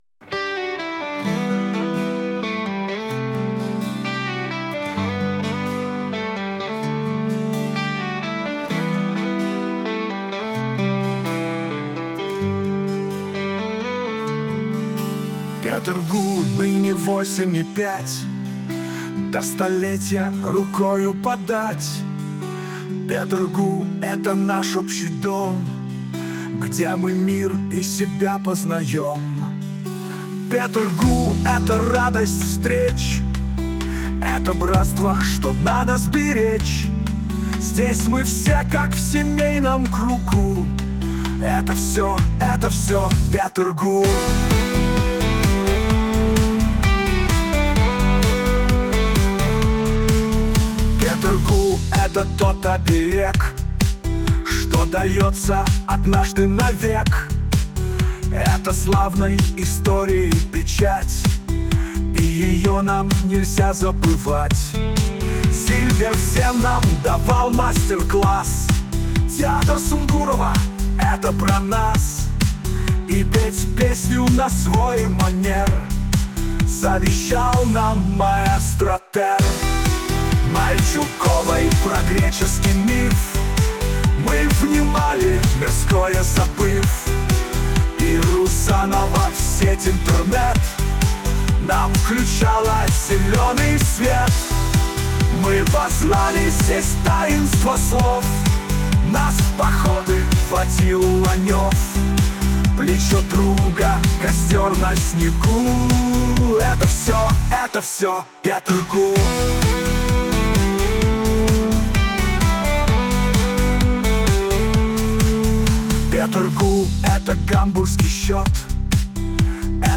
а музыку придумал искусственный интеллект.